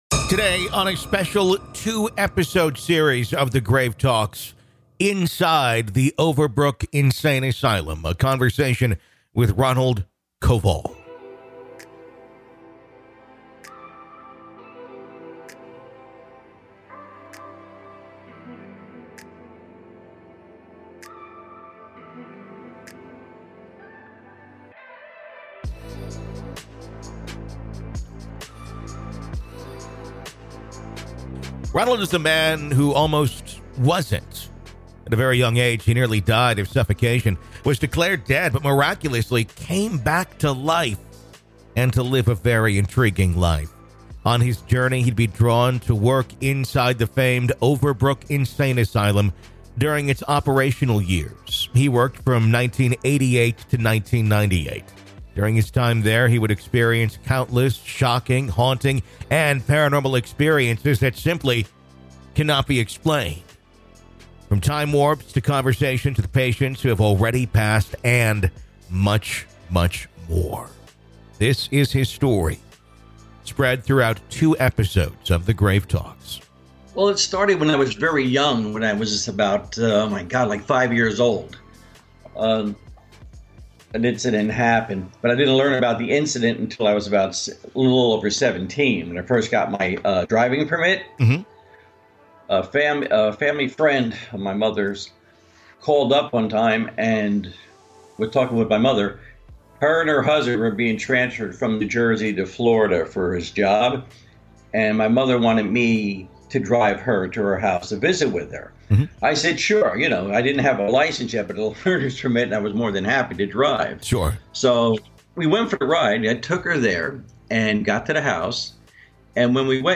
During his time there, he would experience countless haunting and paranormal experiences that can not be explained. From time warps to conversations with patients who have passed, and much more. This is Part One of our conversation.